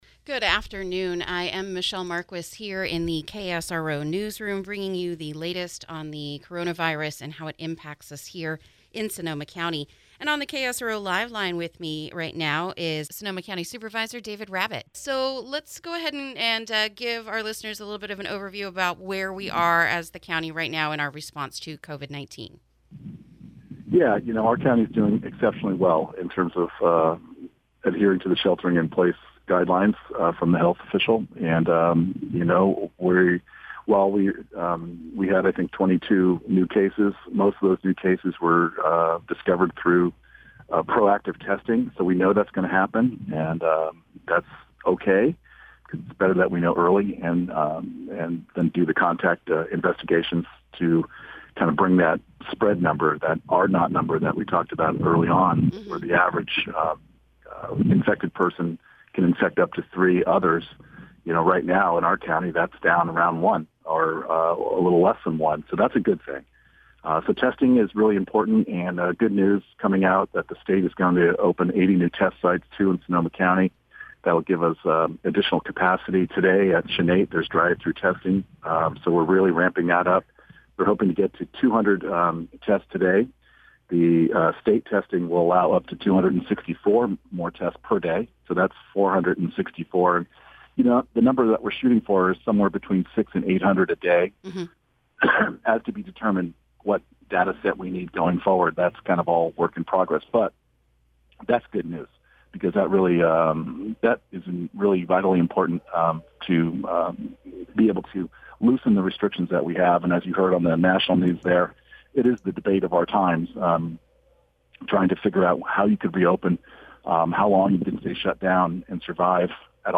INTERVIEW: Supervisor David Rabbitt on Likely Extension of Shelter-In-Place Order, and Expanded Testing